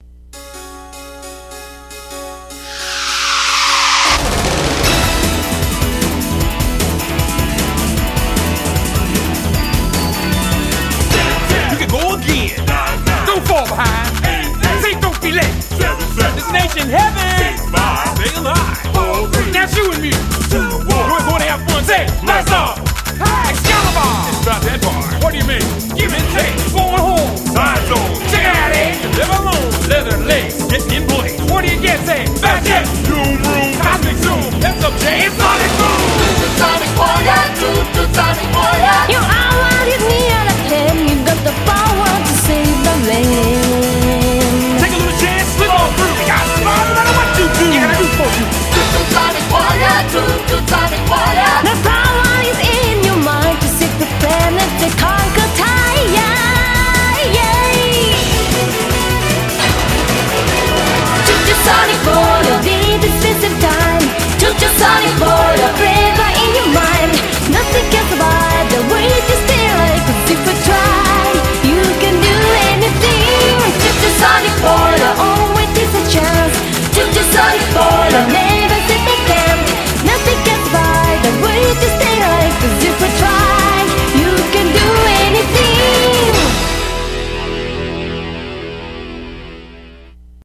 BPM153